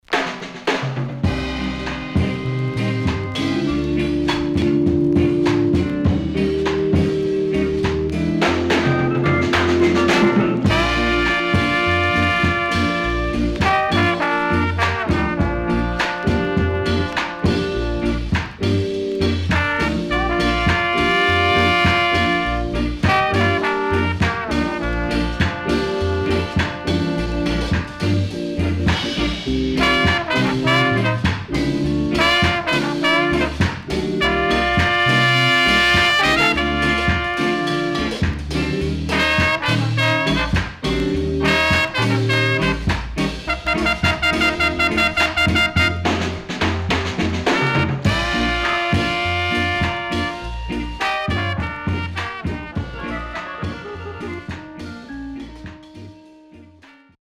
Nice Rocksteady Inst & Ballad